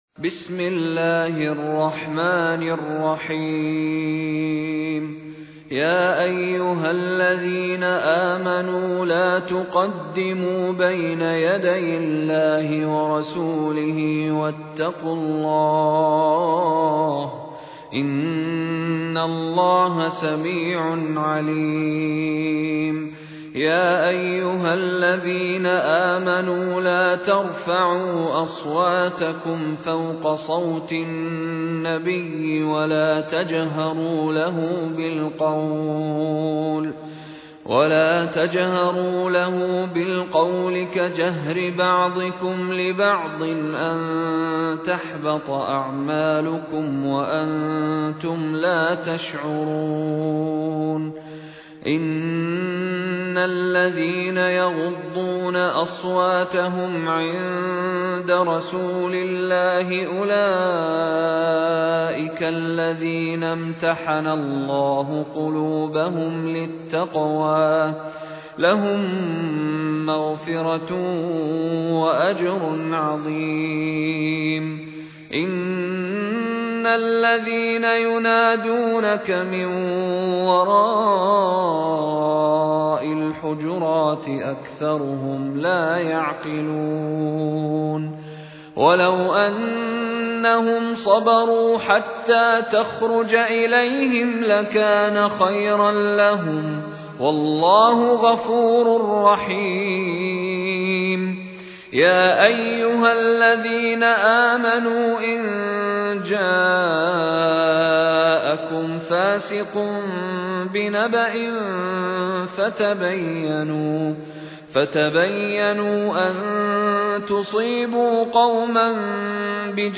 High Quality Quranic recitations and Islamic Lectures from selected scholars